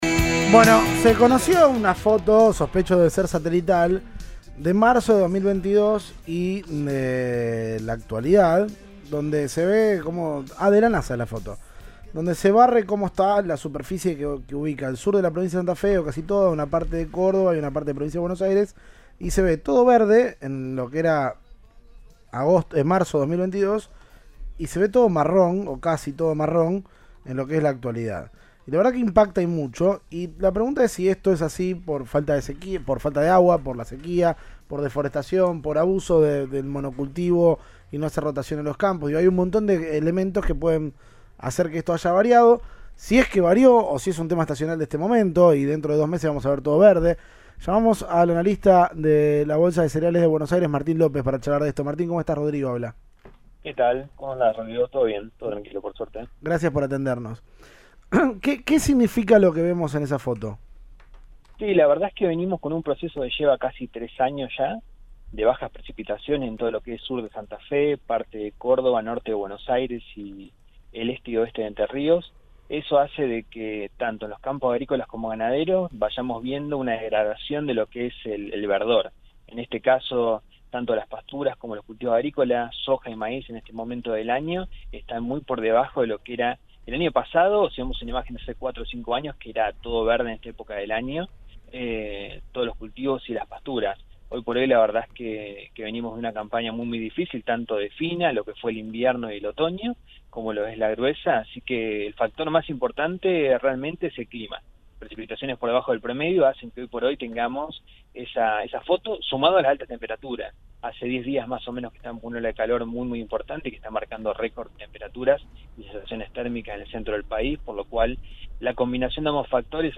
Audio. Analista de la Bolsa de Cereales de Buenos Aires habló sobre la degradación de suelos